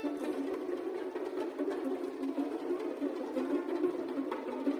ambiance__creepy_violin.wav